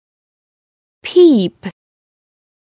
Amazon AWS (pronunciation).